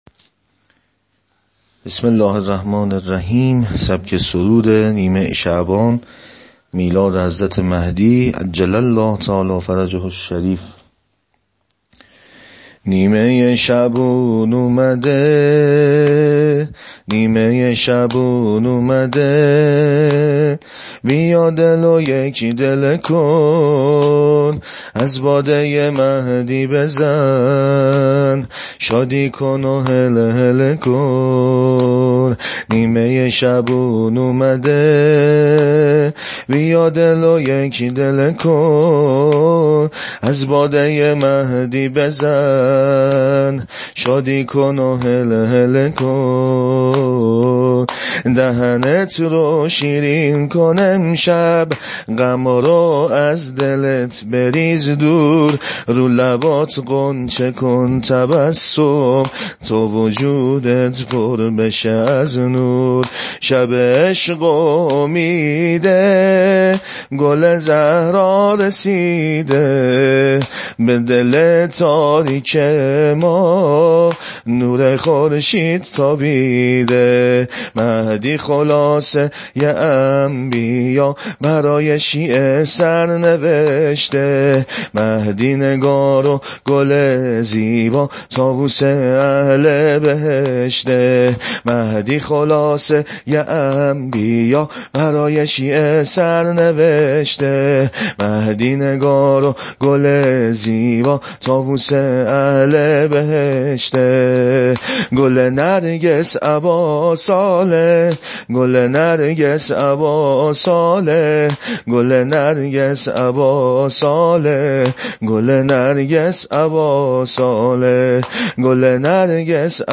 سرود ویژه ی نیمه شعبان میلاد حضرت حجت (عج)
عنوان : سبک سرود امام زمان (عج)